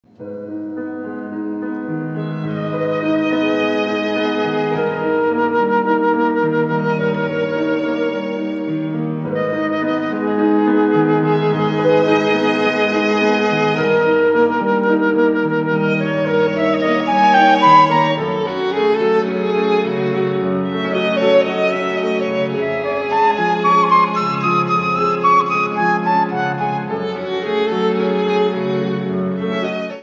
pianoforte
violino
flauto traverso